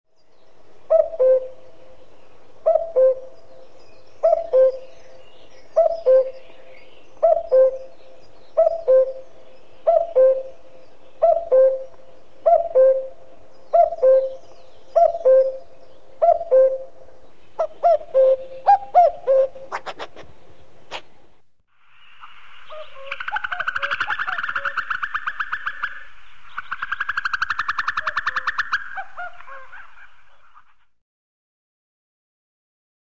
34- Kukučka.mp3